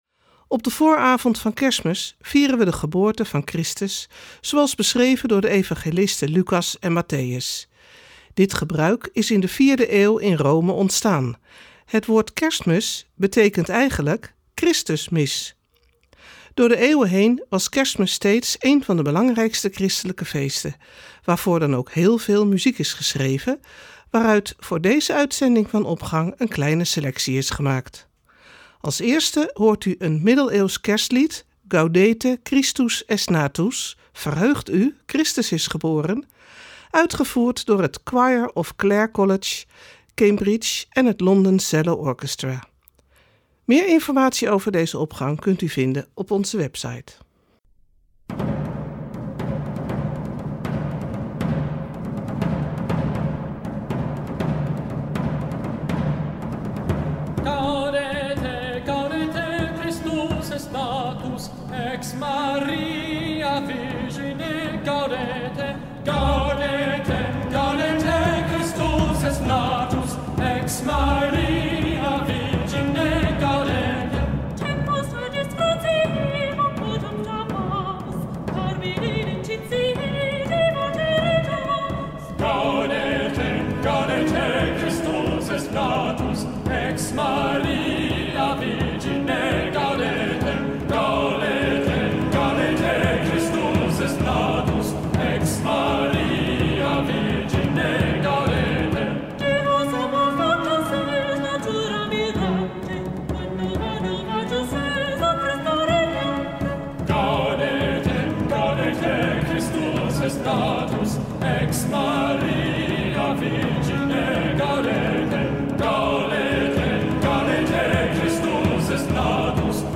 Opening met muziek, rechtstreeks vanuit onze studio.